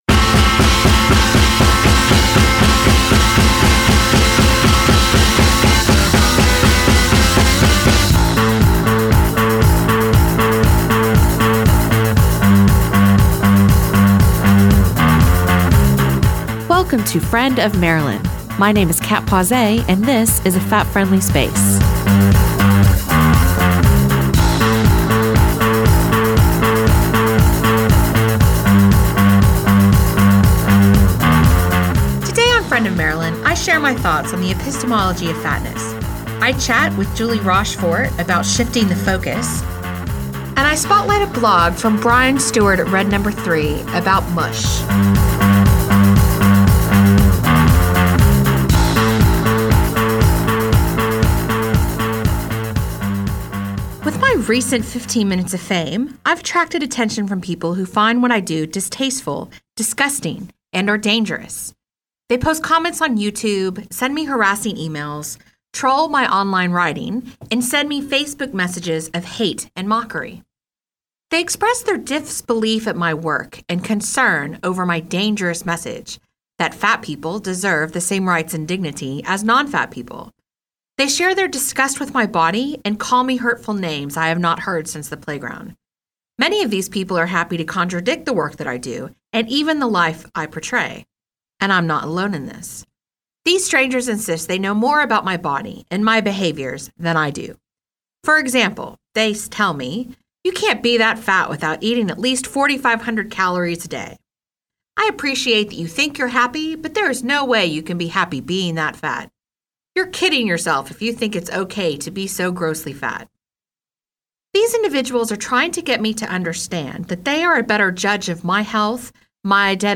Friend of Marilyn was listened to around the world on multiple platforms and was broadcast locally by Manawatū People’s Radio online and on 999AM.